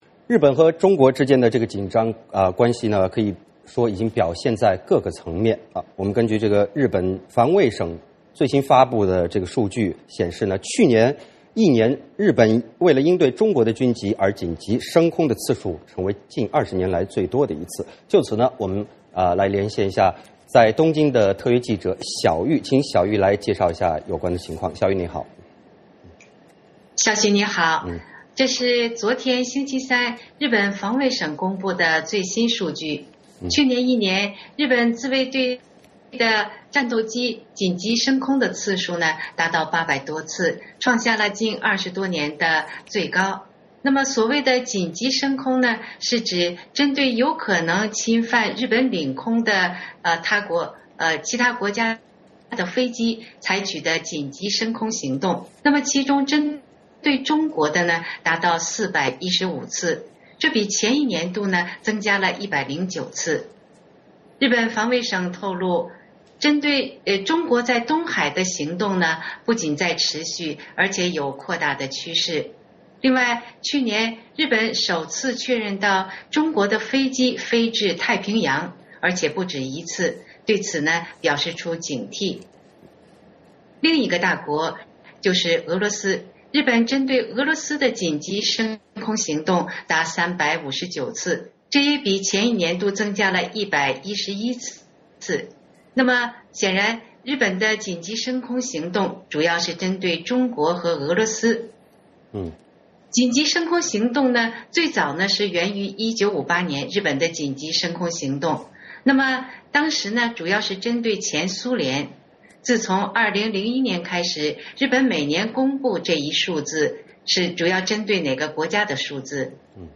VOA连线：日本防卫省:军机2013紧急起飞次数创20年最高纪录